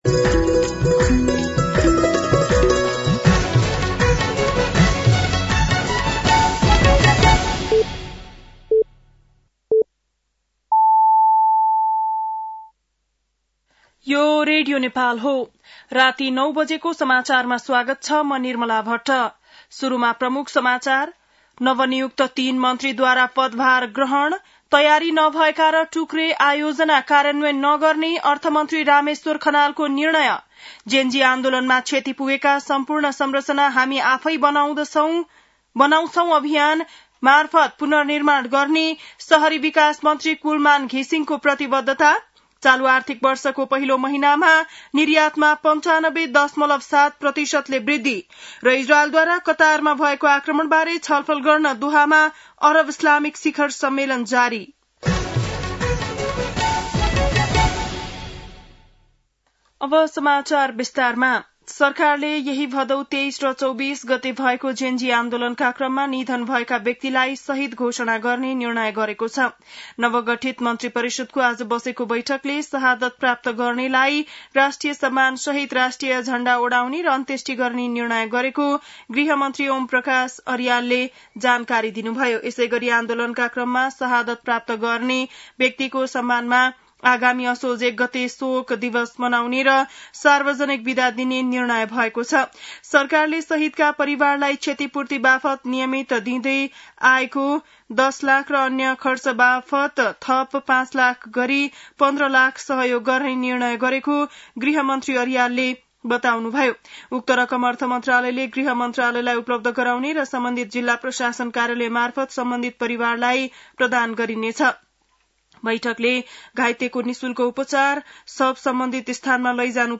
बेलुकी ९ बजेको नेपाली समाचार : ३० भदौ , २०८२